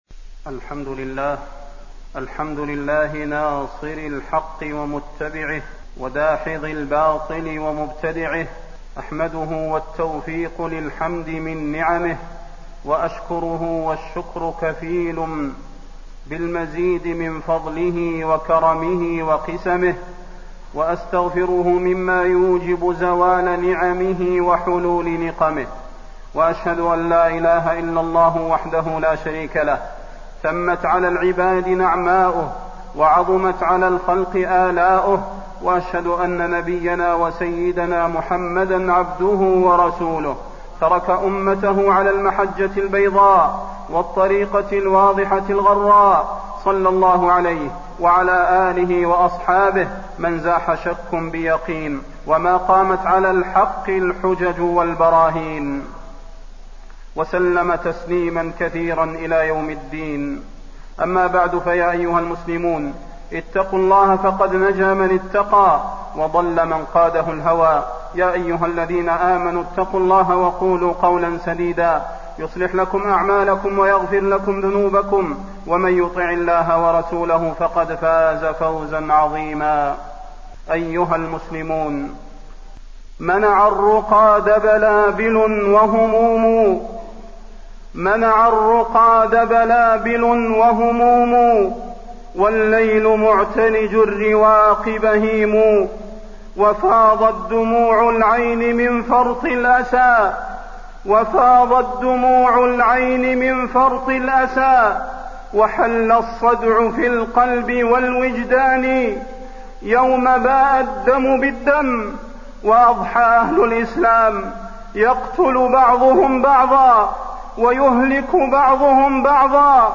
تاريخ النشر ٦ ربيع الثاني ١٤٣٢ هـ المكان: المسجد النبوي الشيخ: فضيلة الشيخ د. صلاح بن محمد البدير فضيلة الشيخ د. صلاح بن محمد البدير وجوب حفظ الأمن والتحذير من الفتن